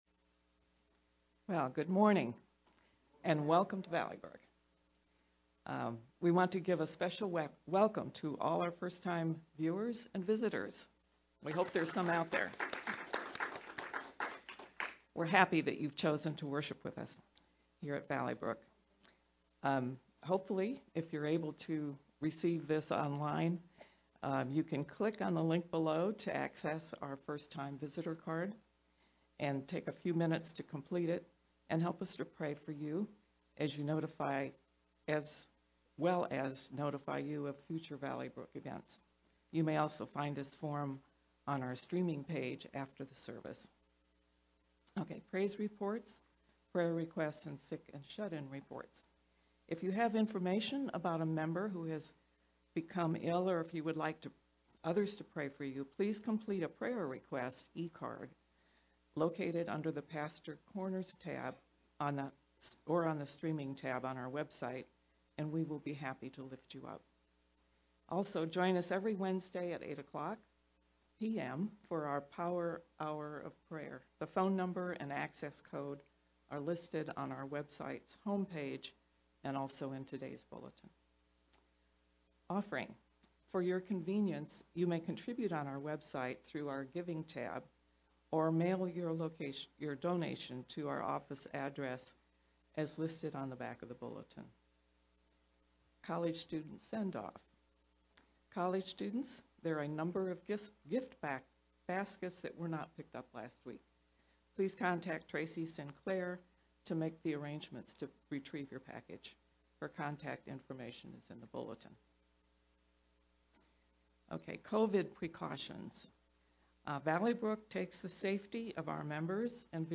VBCC-Aug-8th-Sermon-MP3-CD.mp3